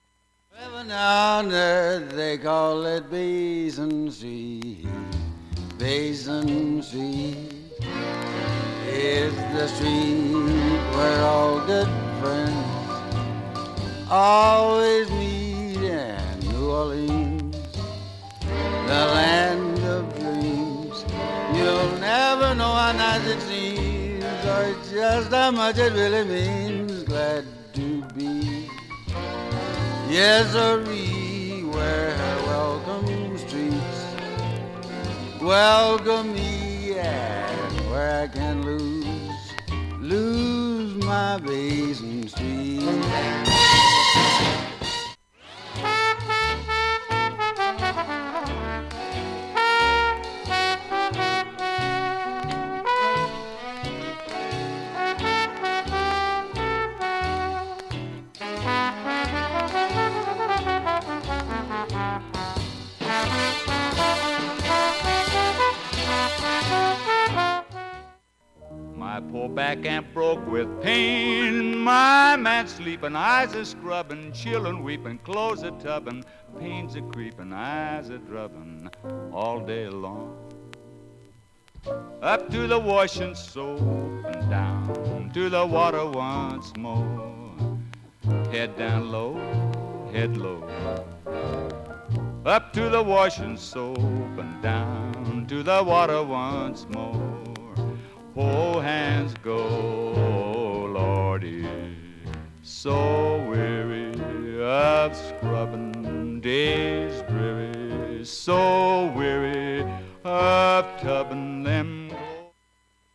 見た目に反して普通にいい音質です。
音質良好全曲試聴済み。
A-4序盤にかすかなプツが２２回出ます。
現物の試聴（上記録音時間2分）できます。音質目安にどうぞ